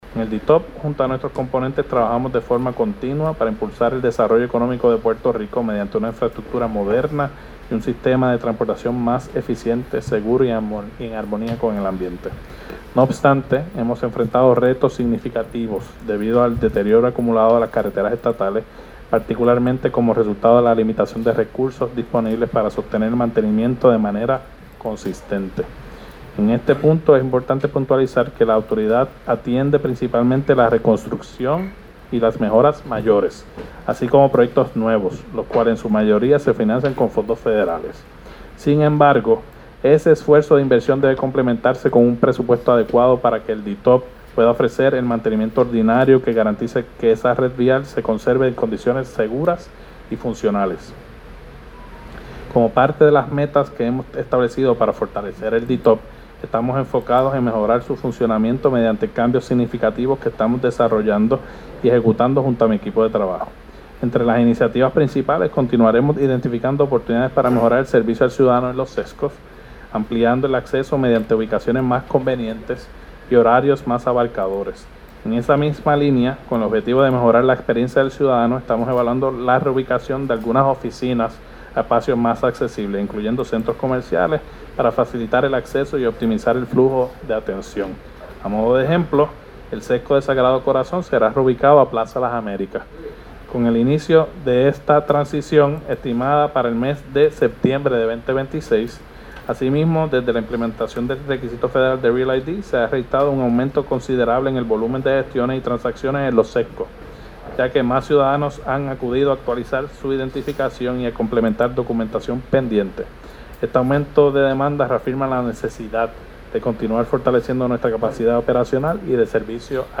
SONIDO-DTOP-VISTA.mp3